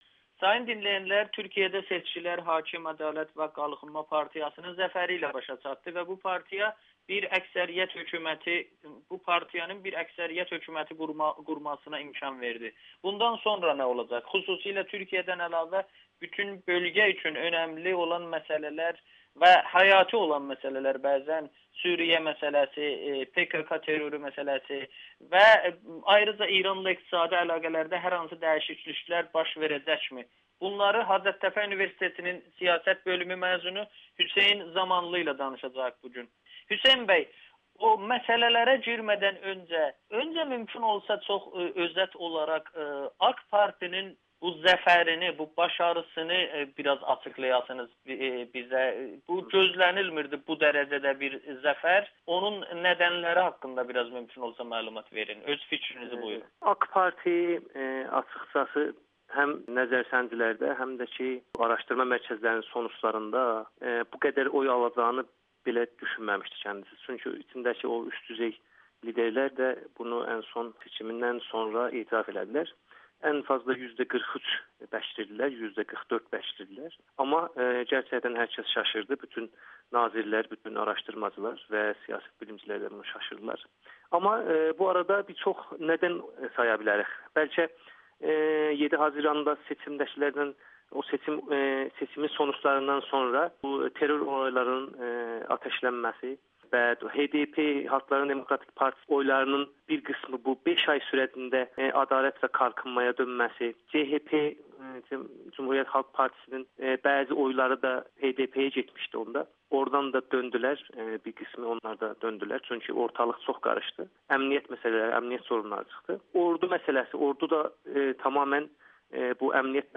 Yeni dönəm AKP hökümətində iqtisadiyyat, xarici siyasət və çözüm prosesi [Audio-Müsahibə]